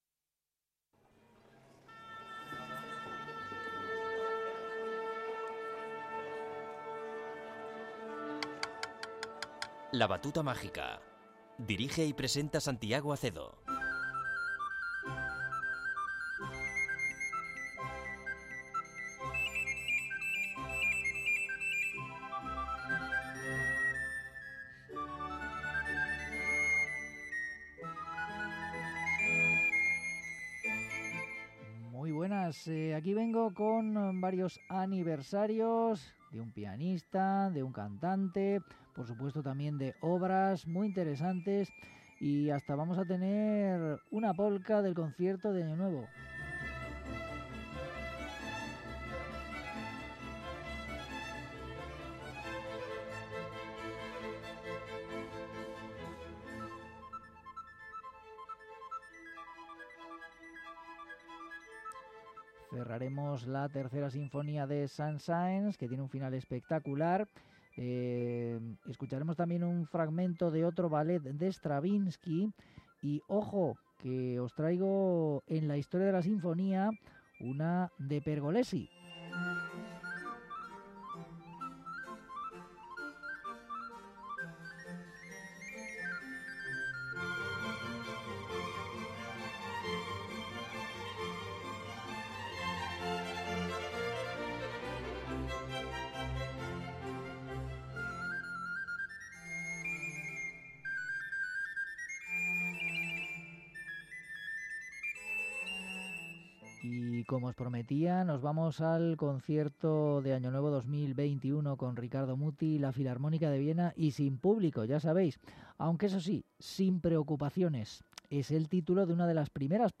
Concierto para 2 oboes